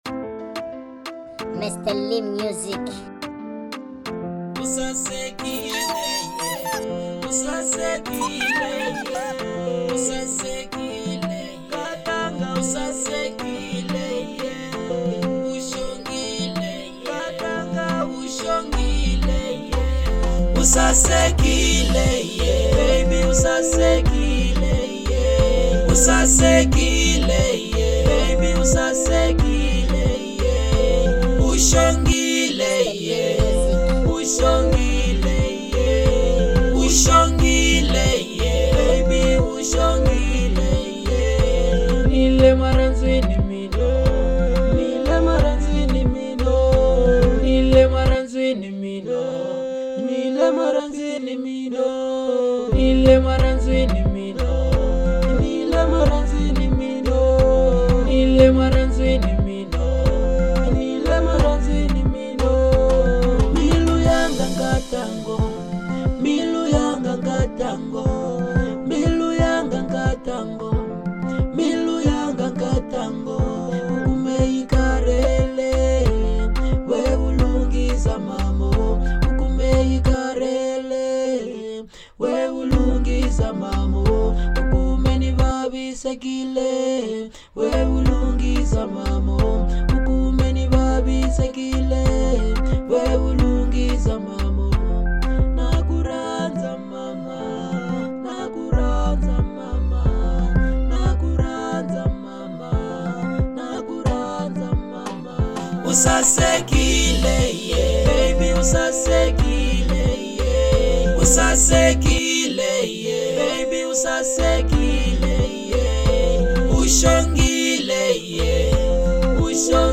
04:07 Genre : Afro Pop Size